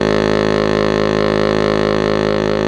SAX BASS S11.wav